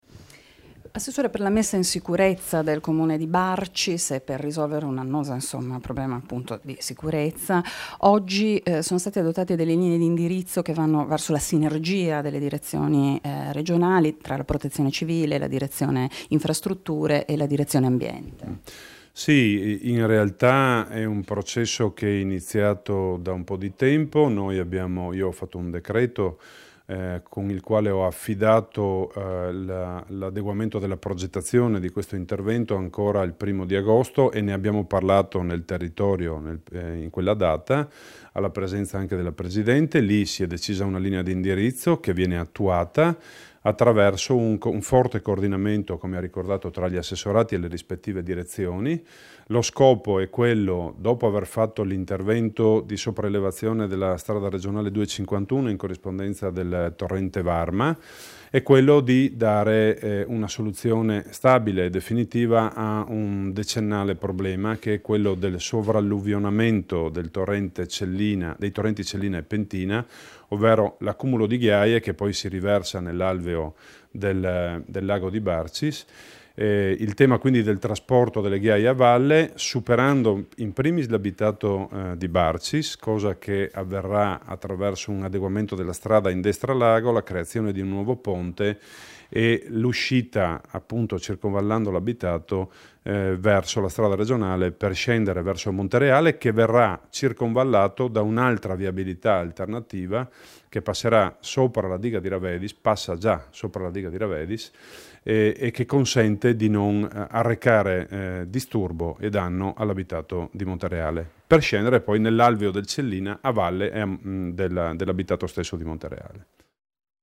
Dichiarazioni di Paolo Panontin (Formato MP3)
sull'intervento che dovrà dare soluzione strutturale al problema del sovralluvionamento dei corsi d'acqua in comune di Barcis, rilasciate a Udine il 7 ottbre 2016